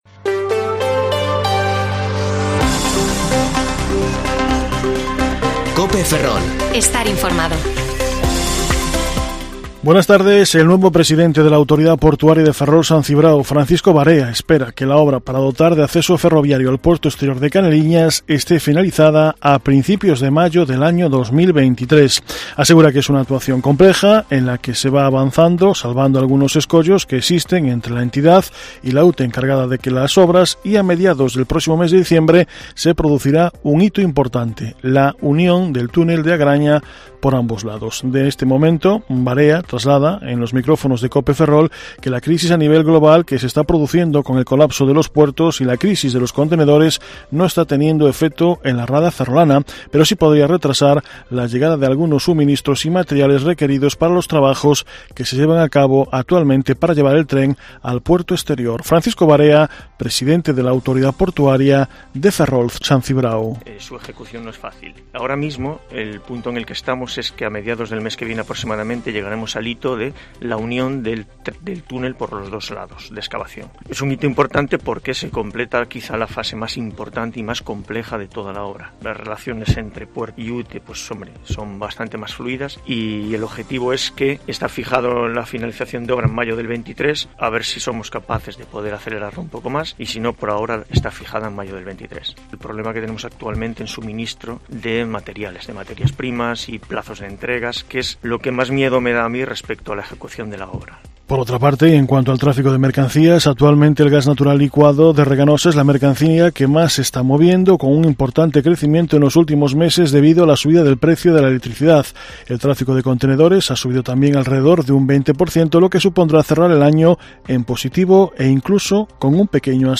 Informativo Mediodía COPE Ferrol 11/11/2021 (De 14,20 a 14,30 horas)